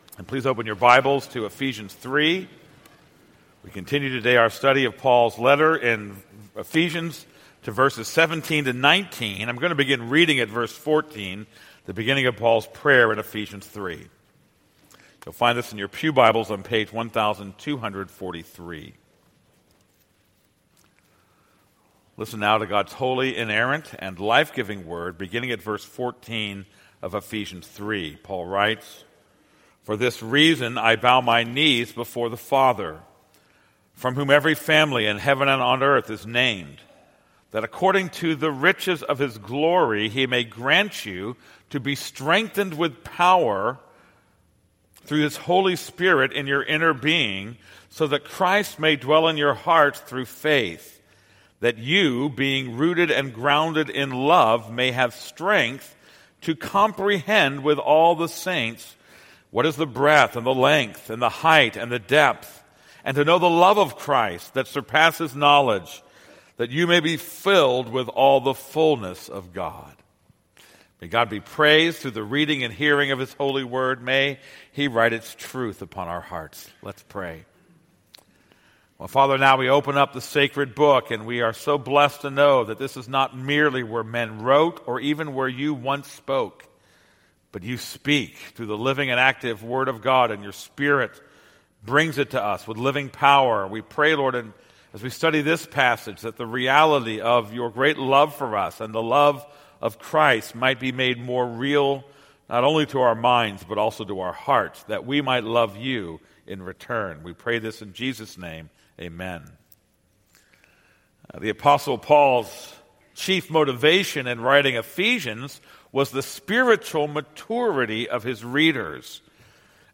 This is a sermon on Ephesians 3:17-19.